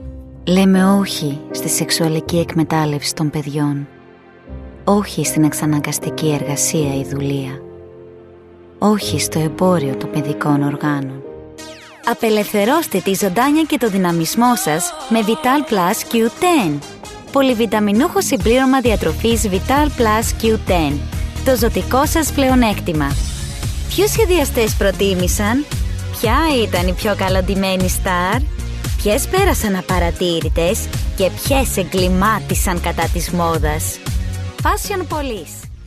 Greek, Female, 20s-40s